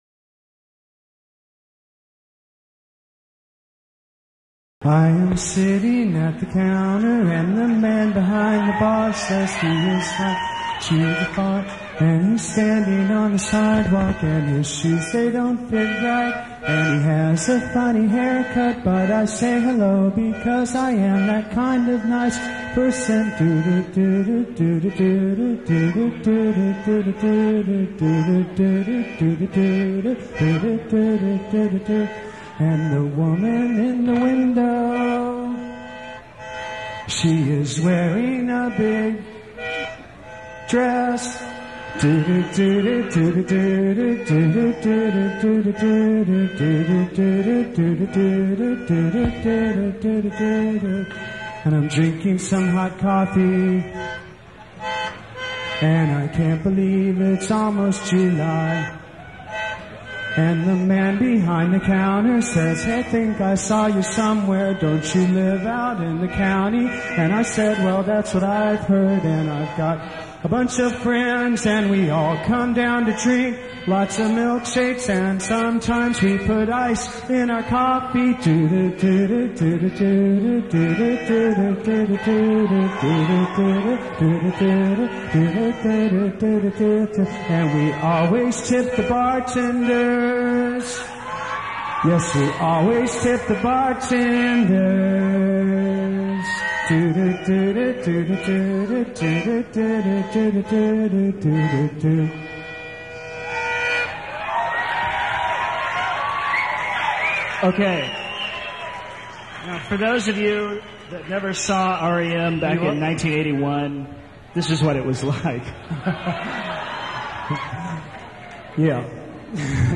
At some point I want to get into more detail about what tracks were included but for time and energy I thought I would just post them here and maybe you will find a chestnut. 1st Ever Podcast - This was my first attempt at a podcast with a potpourri of songs and talk.